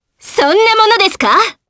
Selene-Vox_Skill4_jp.wav